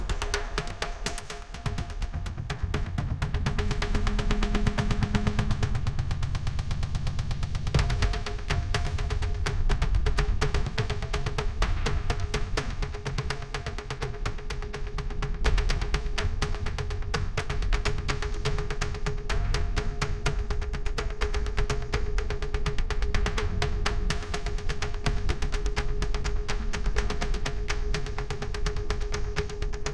Percussive Jungle Tribe
Each was pretty much generated in realtime, taking about 30 seconds per generation.
Percussive_Jungle_Tribe_-_Small_mp3.mp3